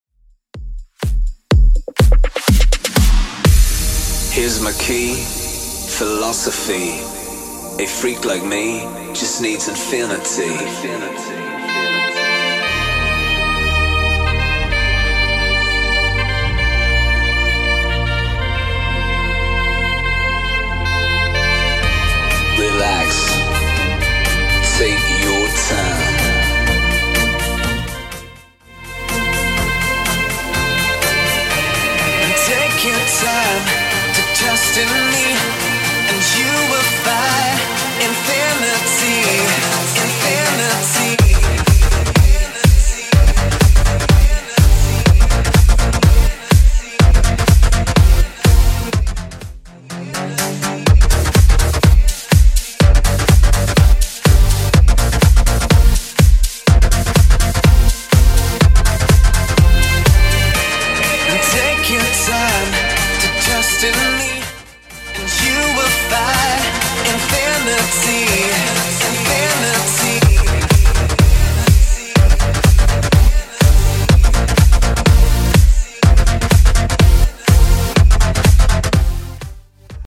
Genre: 80's
BPM: 129